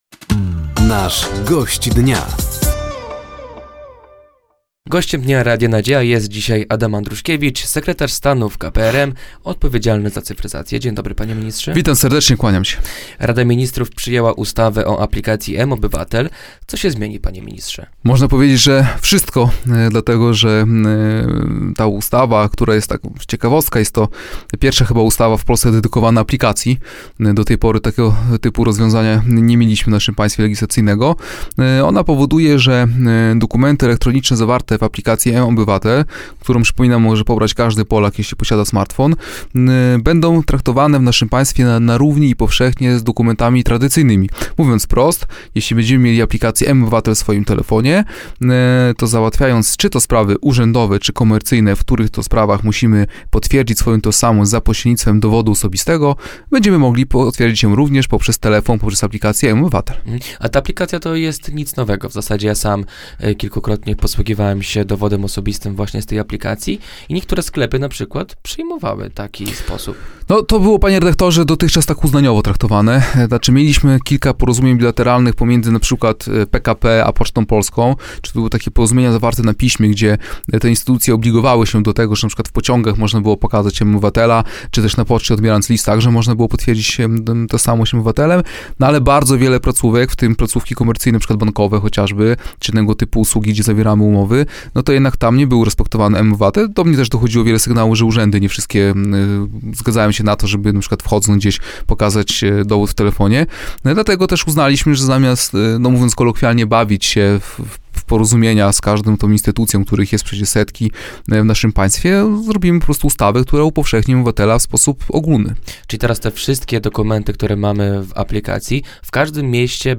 Gościem Dnia Radia Nadzieja był sekretarz stanu w Kancelarii Prezesa Rady Ministrów, Adam Andruszkiewicz. Tematem rozmowy była między innymi aplikacja mObywatel, rozwój województwa podlaskiego i zwiększenie liczby jednostek wojskowych w regonie.